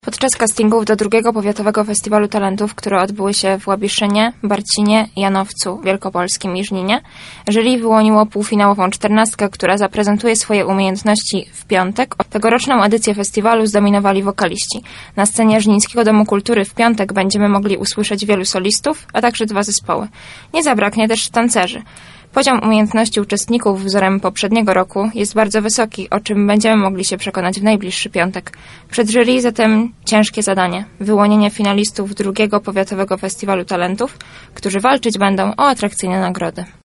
Szerzej na  ten temat mówi jedna z członkini stowarzyszenia.